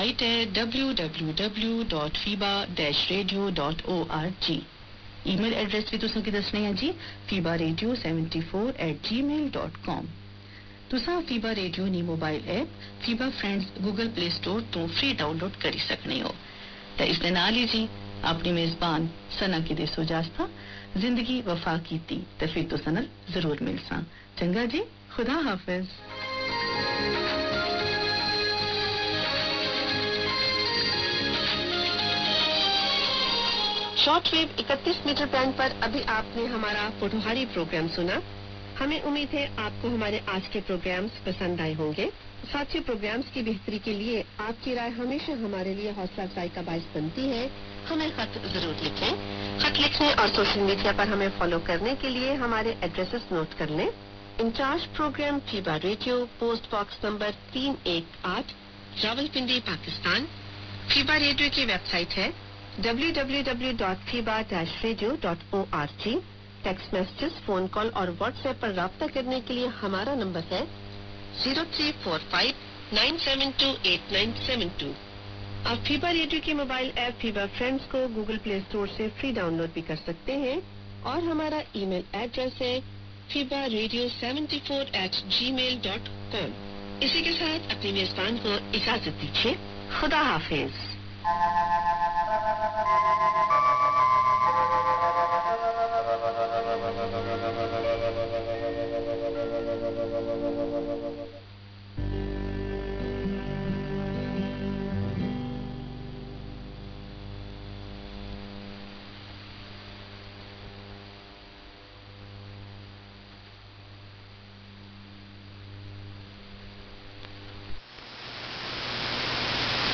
Me viene a la mente el bellbird de Radio New Zealand, y luego FEBA, que antes transmitía desde las Islas Seychelles y ahora emite programas desde diferentes emisoras, sigue usando "Qué amigo tenemos en Jesús".
La radio FEBA a través del transmisor en Al-Dhabbiyah, Emiratos Árabes Unidos (utilizando SDR en Riad, Arabia Saudita) registró el 24 de junio de 2025 y cerró a las 0230 UTC en 9540 kHz: